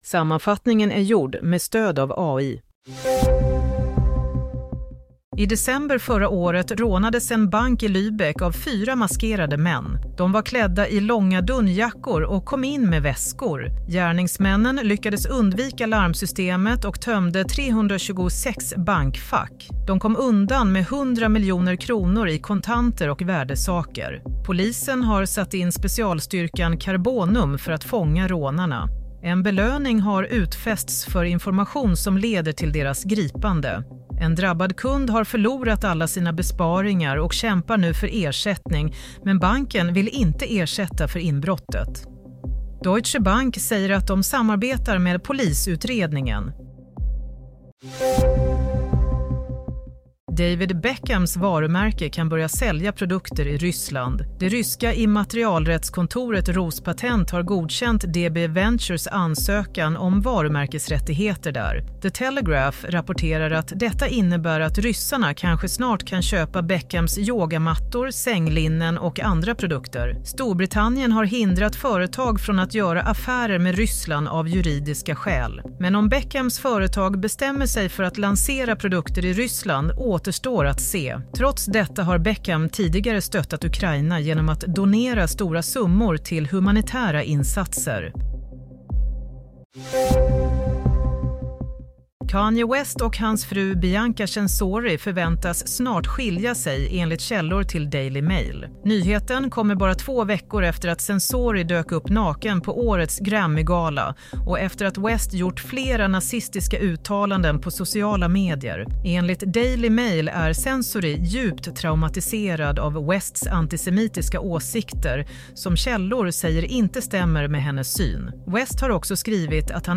Nyhetssammanfattning - 14 februari 07:00
Sammanfattningen av följande nyheter är gjord med stöd av AI.